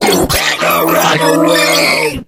8bitvirus_start_vo_04.ogg